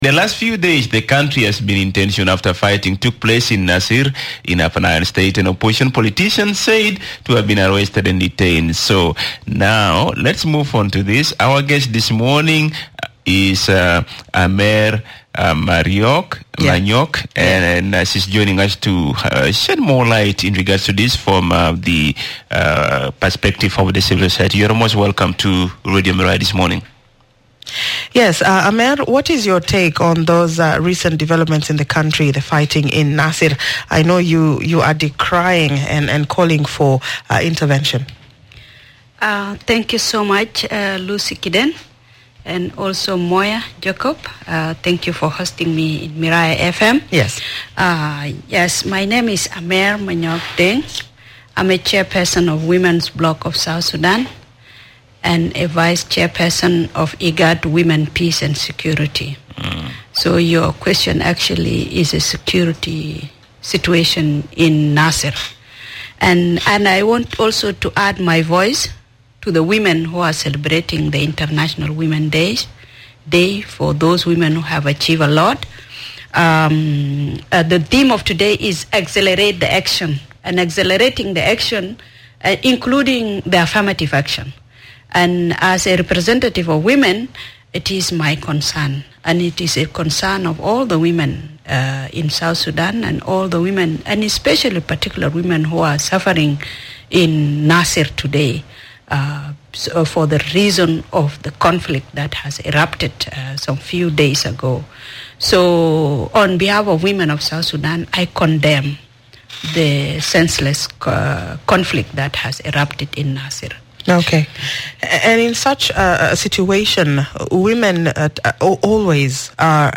MBS. Discussion on the Crisis in Nasir and De-escalating Tensions in the Country